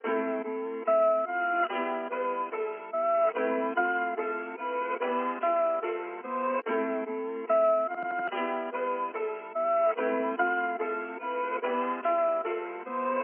爵士和弦钢琴循环曲120 bpm
描述：爵士和弦钢琴。柔和，温和的电钢琴，带有爵士和谐。音乐循环。